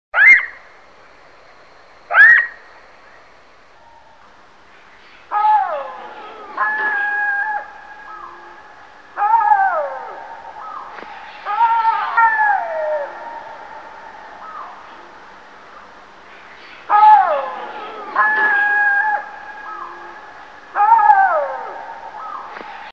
Здесь собраны разнообразные аудиозаписи: от протяжного воя до рычания и коммуникационных сигналов.
Звуки шакалов